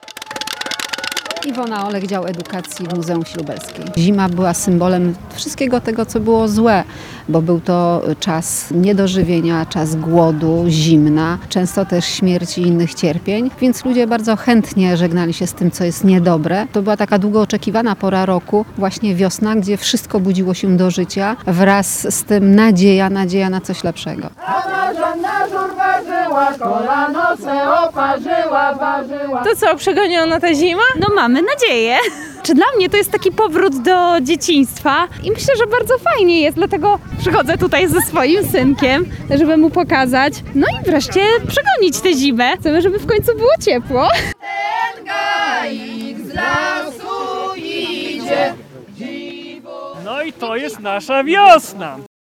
W Muzeum Wsi Lubelskiej przywołano wiosnę. Mieszkańcy Lublina utopili Marzannę, przystroili gaik, a także wspólnie zaśpiewali wiosenne kolędy.
Pożegnanie zimy w Muzeum Wsi Lubelskiej